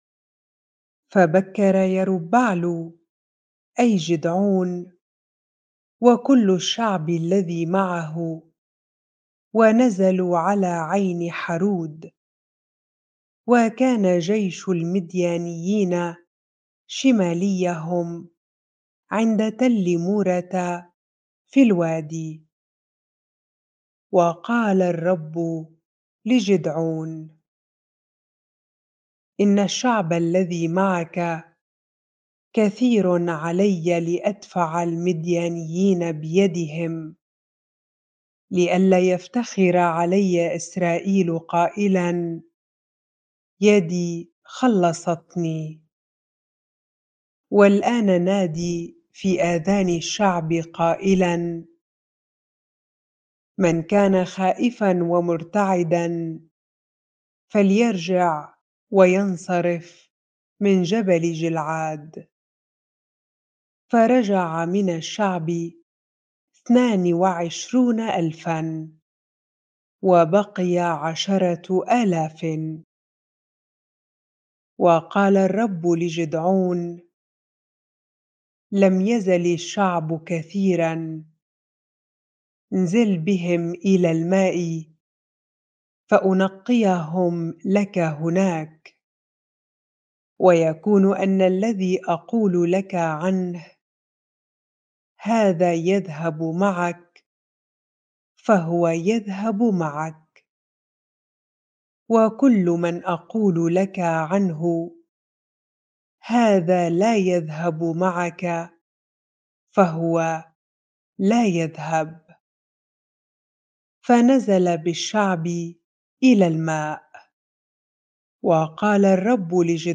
bible-reading-Judges 7 ar